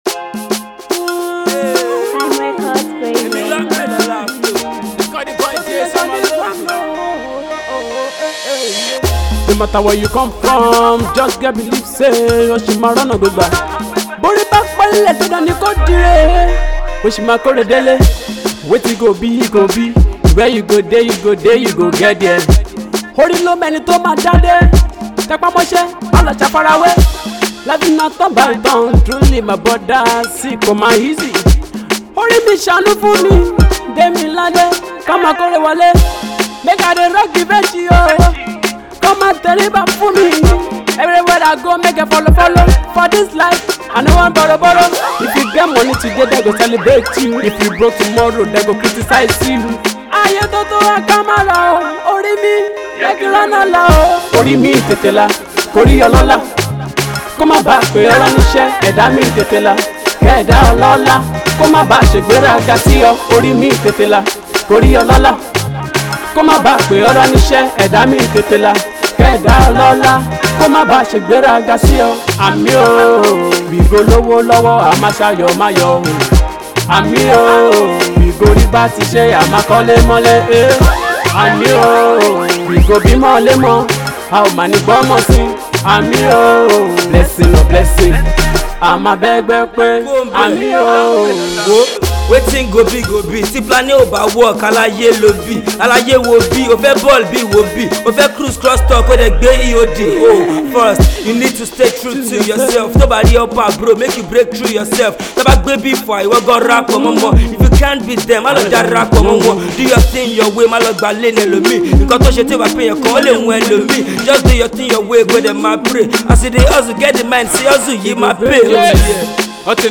prayerline record